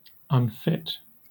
Ääntäminen
Southern England UK : IPA : /ʌnˈfɪt/